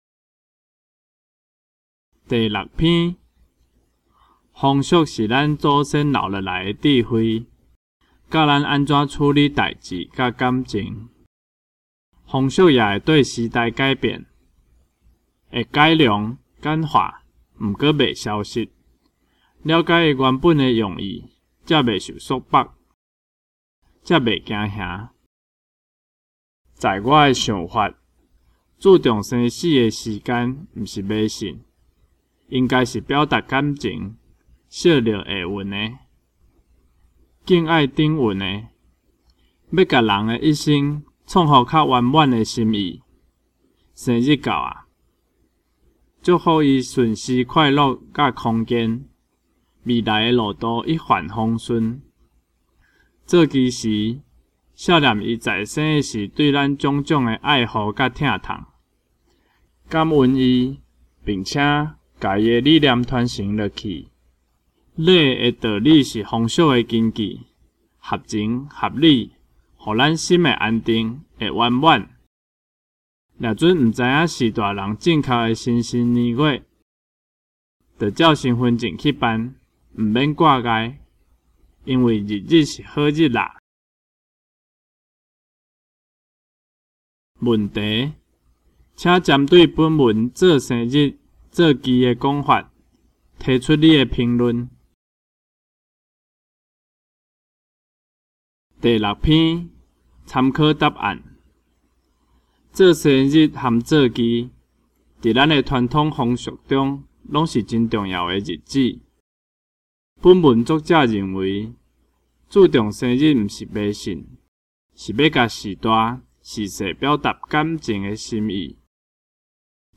四.文章朗讀及評論
47.文章朗讀及評論第6篇.MP3